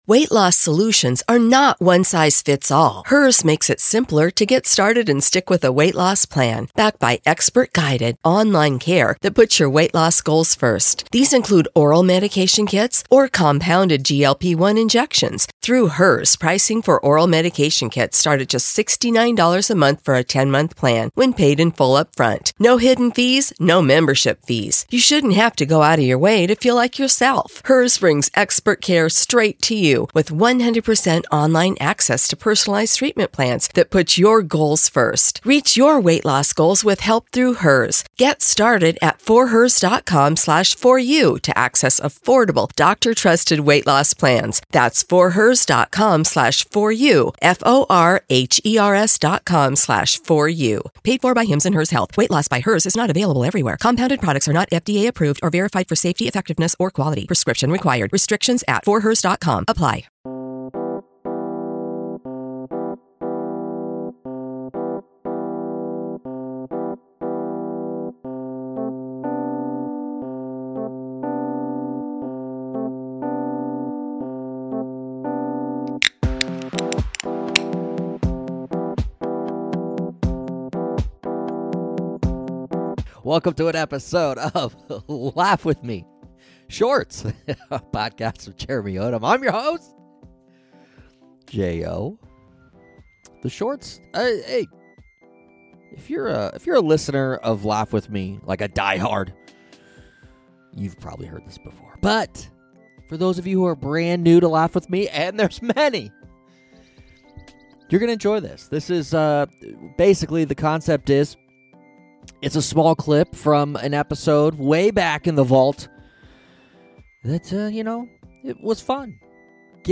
Comedy, Stand-up